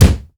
punch_low_deep_impact_01.wav